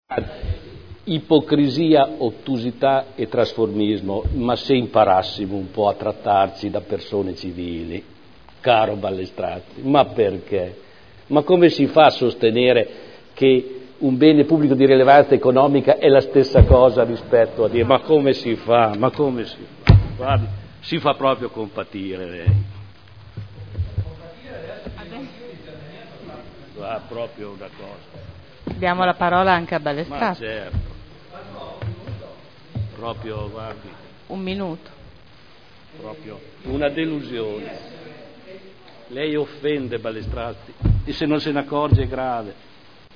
Seduta del 27/06/2011.
Dibattito